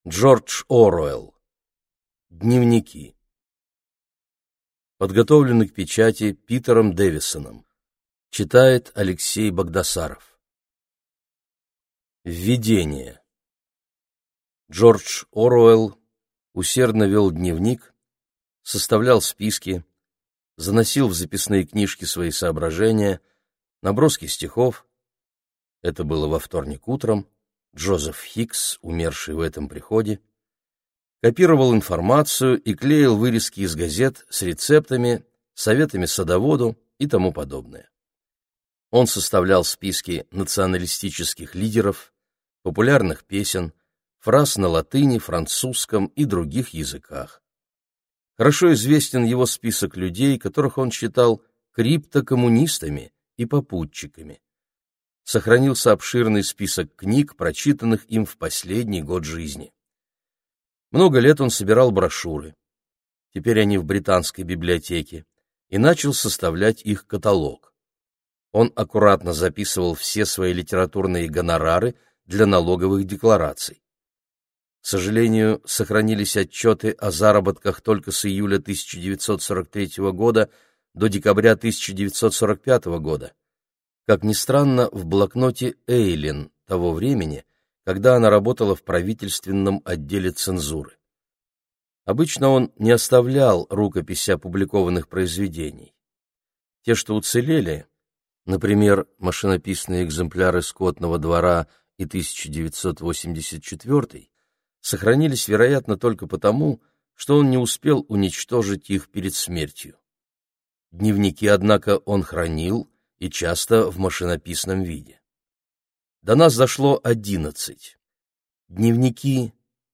Аудиокнига Дневники | Библиотека аудиокниг